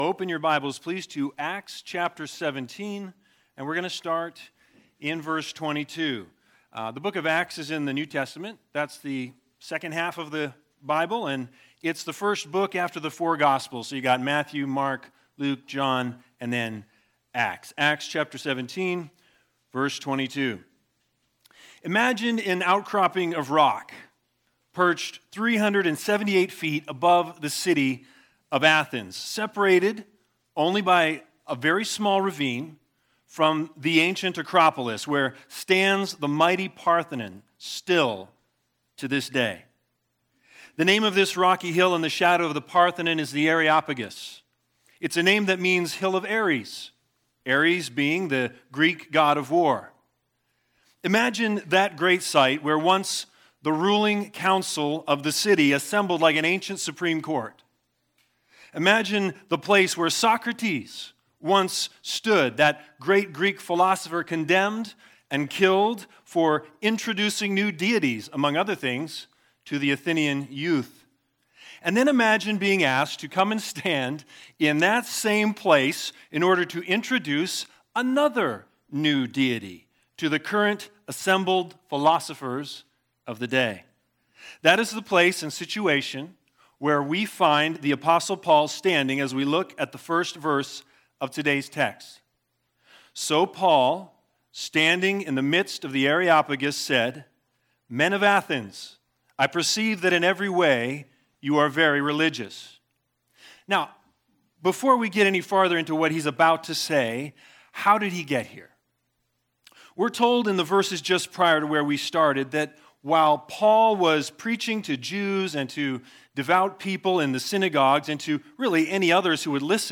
Acts 17:22-34 Service Type: Special Sermons BIG IDEA